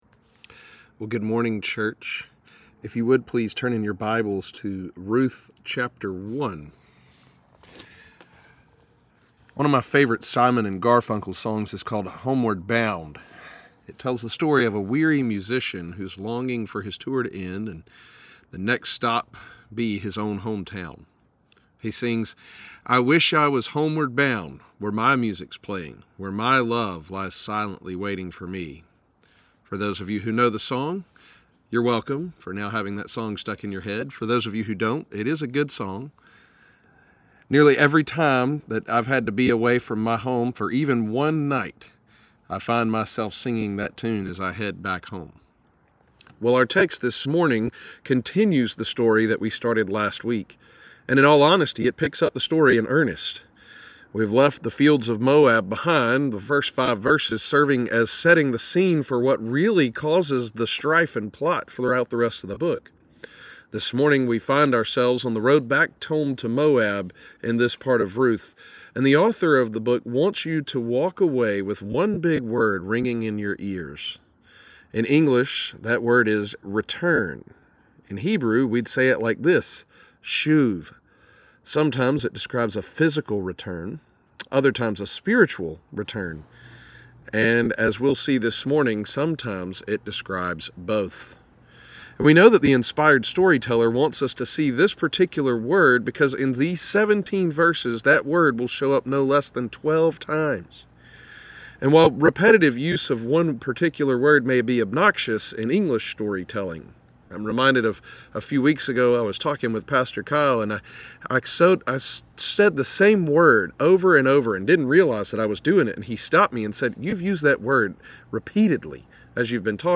**The first 3 minutes of audio had to be rerecorded, so the sound quality will be different just after the 3 minute mark.**